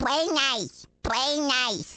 One of Baby Luigi's voice clips from the Awards Ceremony in Mario Kart: Double Dash!!